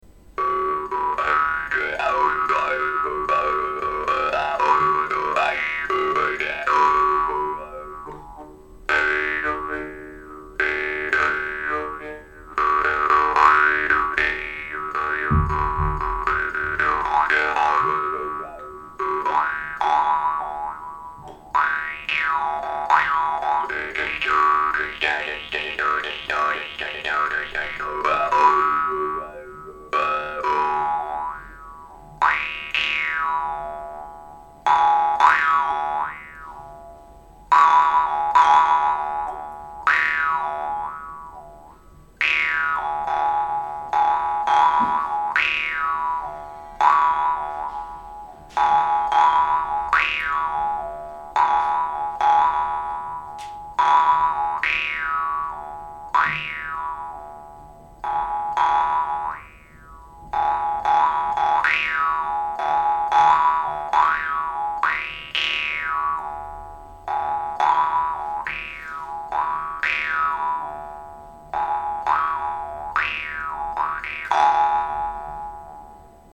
Material: steel
The gap of this unique khomus is regulable and it allows you to change the sound of the khomus.
We also have another regulable jew's harp which we are going to sell after the first one.
Tongue length: 80 mm
Basket: jew’s harp + wooden box
Demo music: one